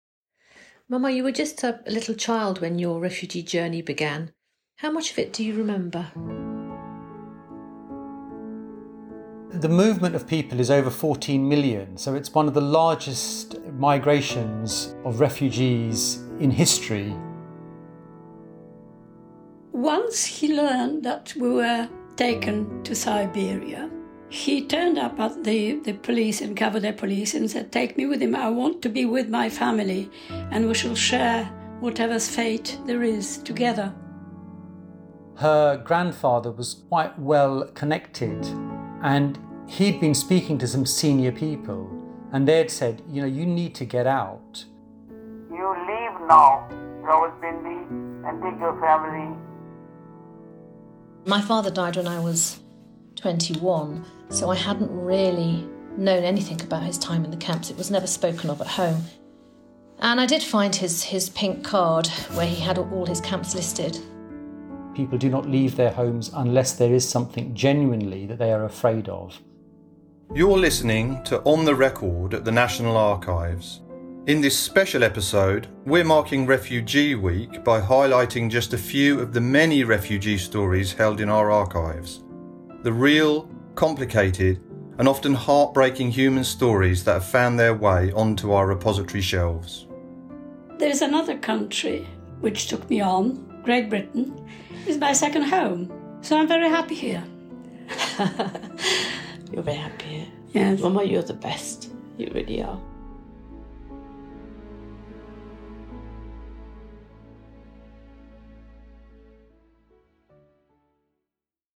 To mark Refugee Week (15-21 June) we are sharing just a few of the many refugee stories held in our vast collection. In a special episode we will be going beyond the documents, as two of our records specialists interview their own parents about their refugee experiences.